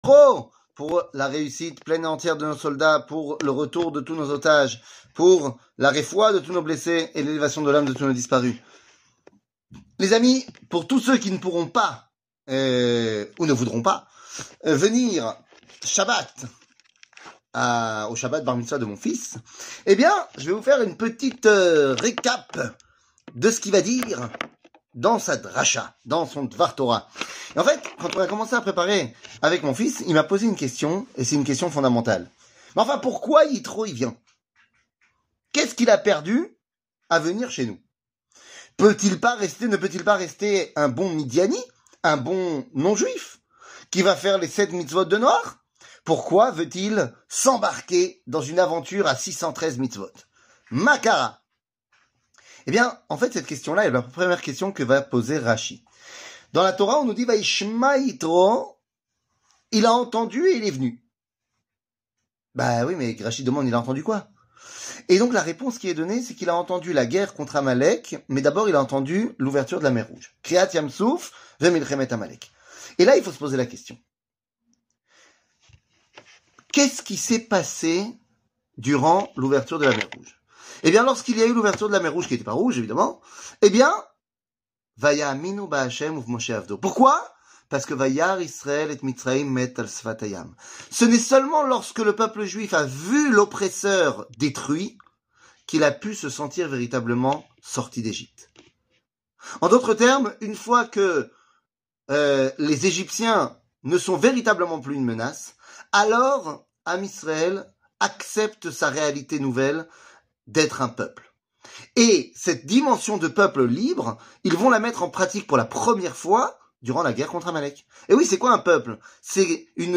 שיעור מ 01 פברואר 2024 05MIN הורדה בקובץ אודיו MP3 (5.43 Mo) הורדה בקובץ וידאו MP4 (8.96 Mo) TAGS : שיעורים קצרים